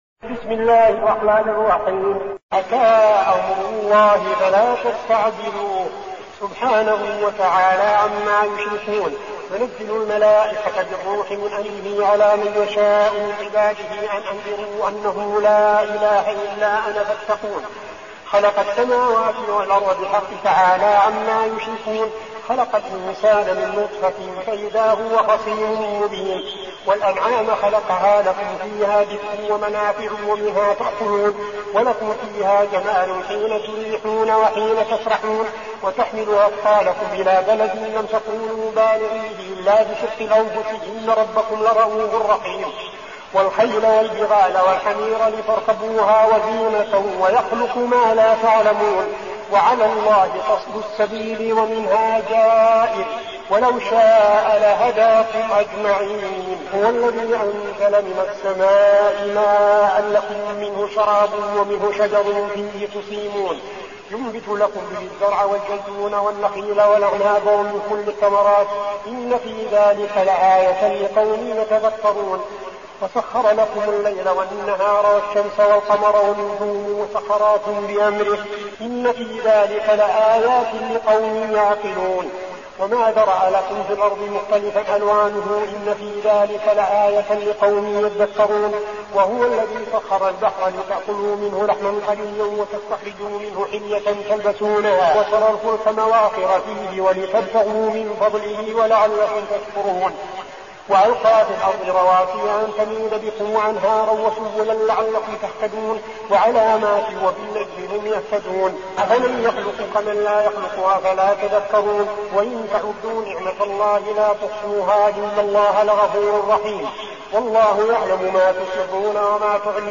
المكان: المسجد النبوي الشيخ: فضيلة الشيخ عبدالعزيز بن صالح فضيلة الشيخ عبدالعزيز بن صالح النحل